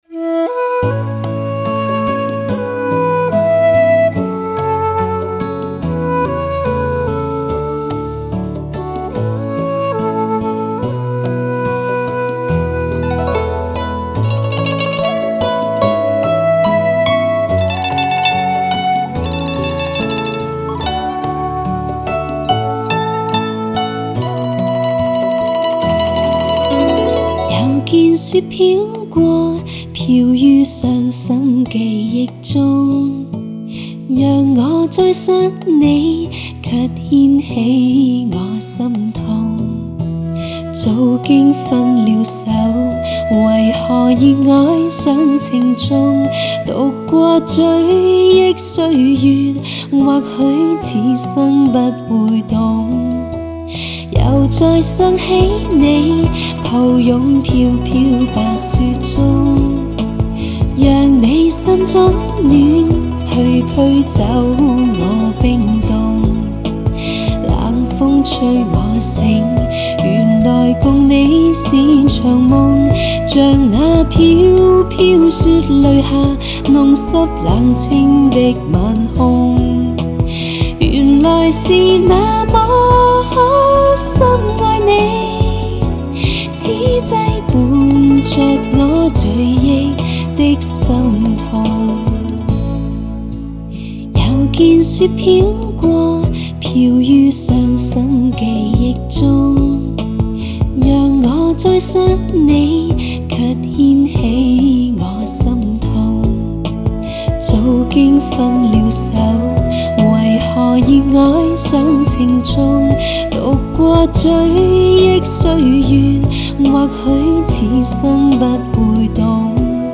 天成丽质气自华，发烧界最为柔美自然的极品女声。